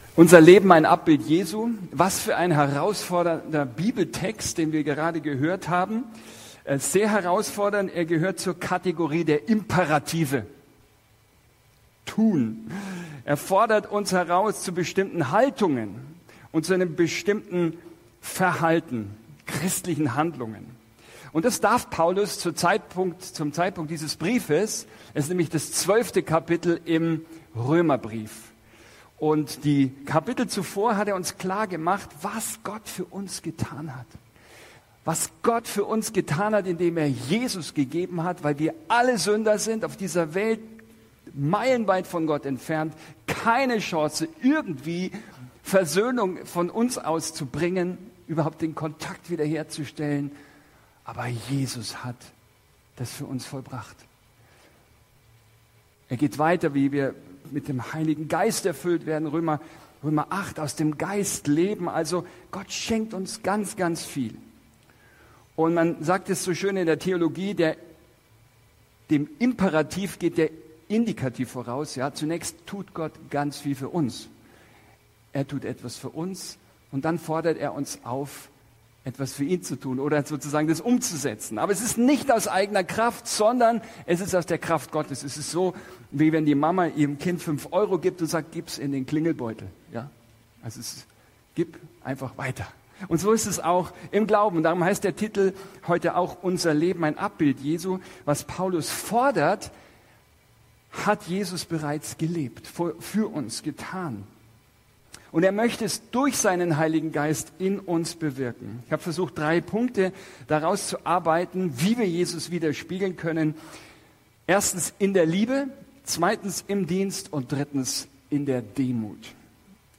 Ein Studienblatt zur Predigt ist im Ordner “Notizen” (Dateien zum Herunterladen) verfügbar